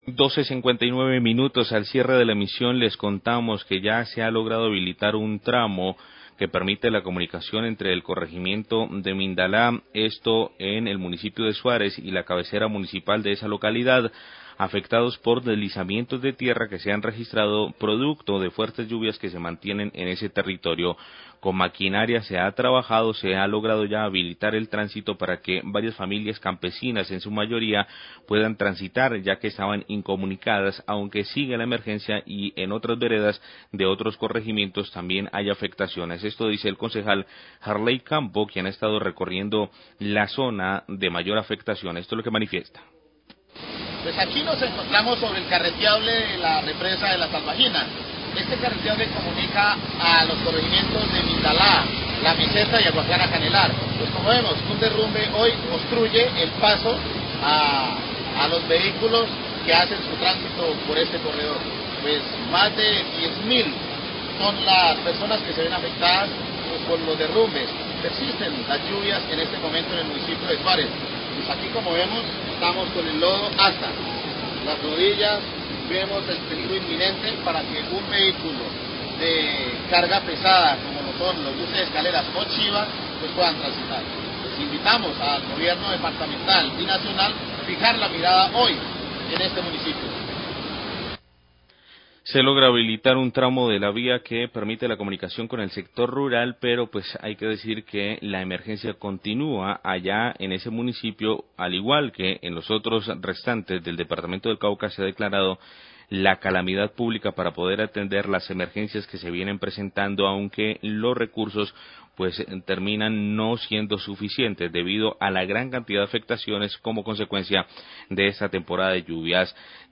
Radio
Con el uso de maquinaria amarilla se logra habilitar un tramos de la vía carreteable en la represa La Salvajina, que comunica a la vereda Mindalá con la cabecera municipal de Suárez. El concejal Jarley Campo habla del precario estado del carreteable afectado por el invierno.